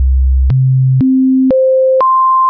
The audio link below demonstrates the variable loudness of tones at the same decibel level. Each tone is 0.5 second in duration starting with C2 (65.41 Hz), followed by C3, C4, C5 and then C6 (1046.5 Hz).
C2 then C3 then C4 then C5 then C6
c2-c3-c4-c5-c6.wav